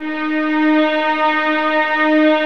VIOLINS FN-L.wav